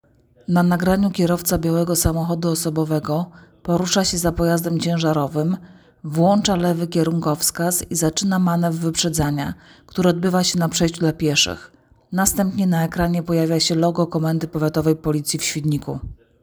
Nagranie audio audiodeskrypcja filmu